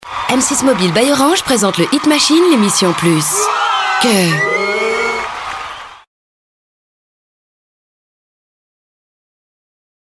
Comédienne, voix off, voix méduim, naturelle et dynamique, Publicitès, Voices Over ou institutionnels
Sprechprobe: Werbung (Muttersprache):
Female Voice, Méduim, Smiling, natural and dynamic! Advertising, Game, Voice Over and more...since 18 years!